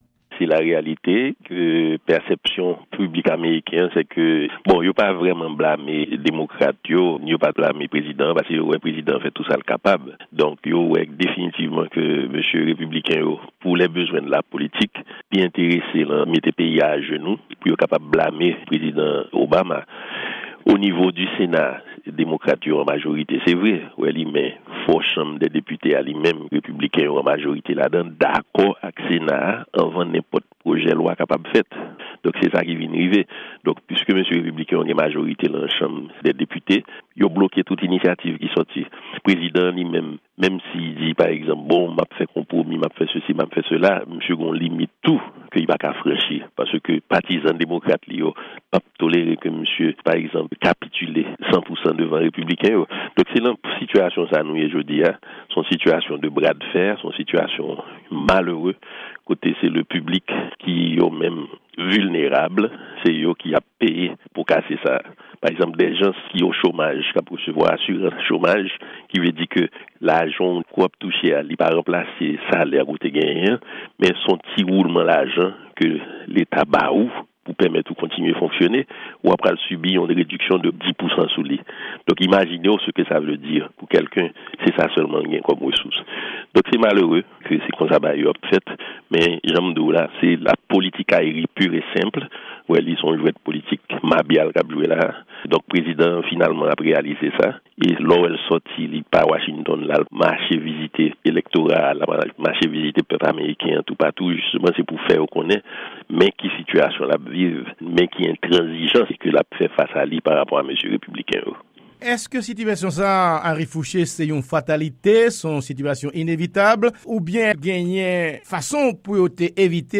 Ekstrè entèvyou